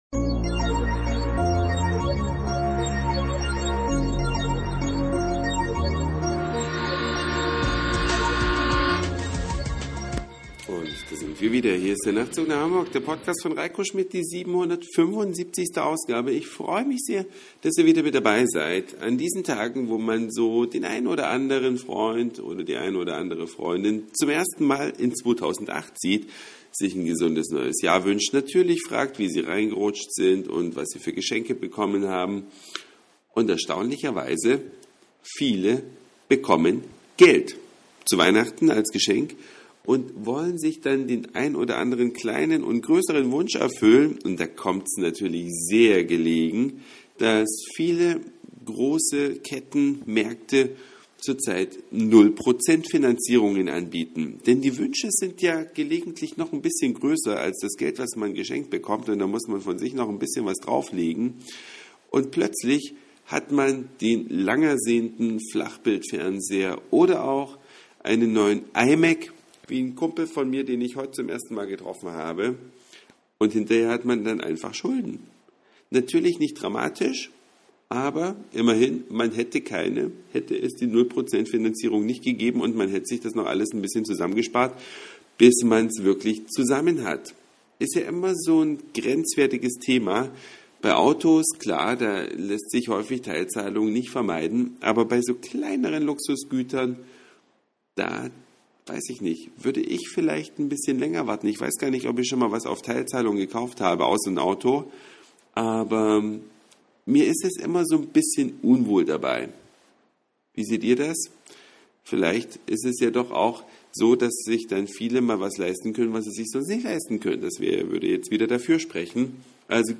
Ein Anruf im Büro mit